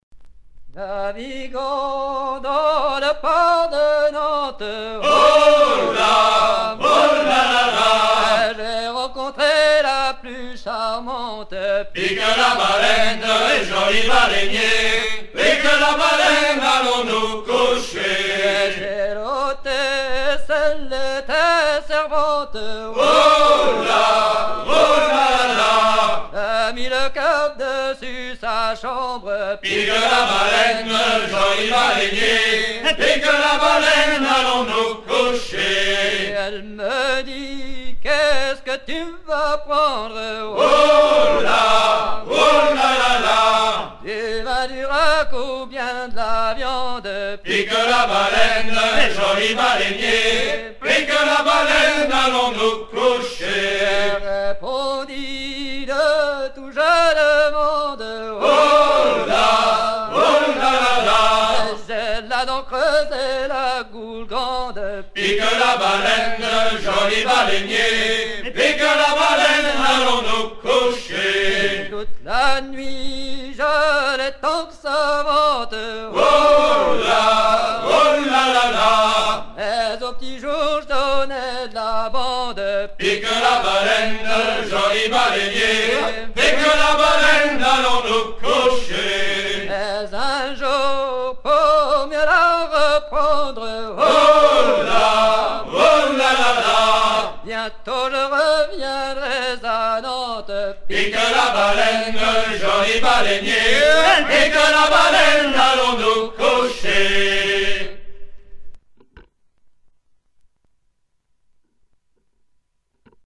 Version recueillie auprès de cap-horniers nantais vers 1960
à virer au cabestan
Genre laisse
Pièce musicale éditée